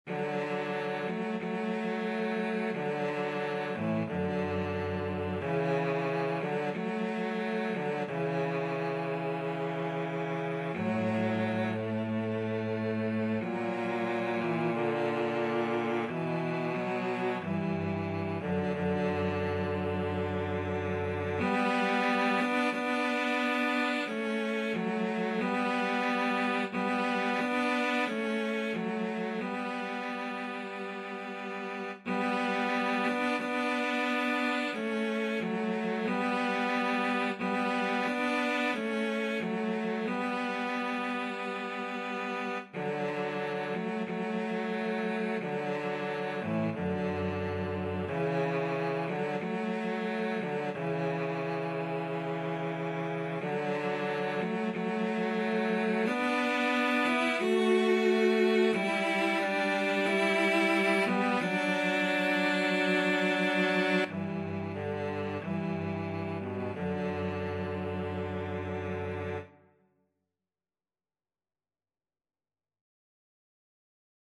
Free Sheet music for Cello Duet
Cello 1Cello 2
D major (Sounding Pitch) (View more D major Music for Cello Duet )
4/4 (View more 4/4 Music)
=180 Largo